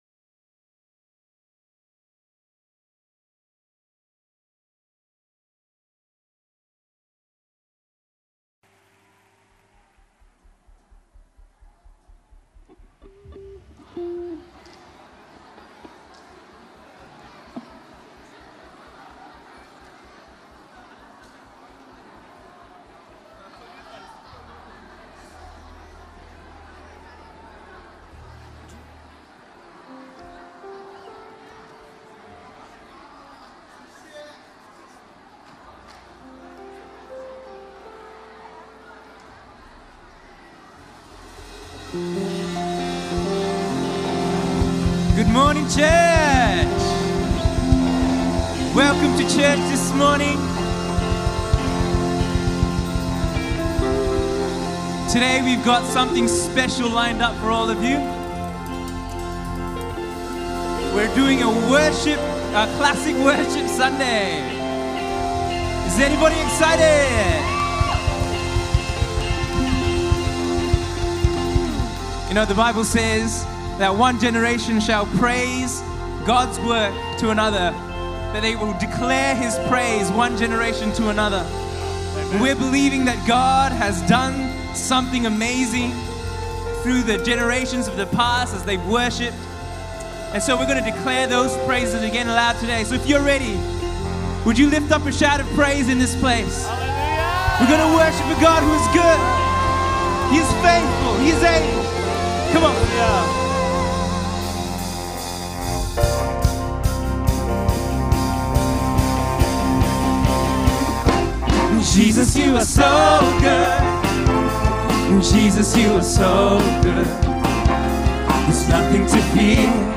So that’s why last Sunday, we did a “classics” set – a set of enduring songs from the last 20 to 30 years of the praise and worship movement; songs that most of us who have been in the church for a long time would have grown up with; songs that have shaped the worship landscape and paved the way for what God is doing today.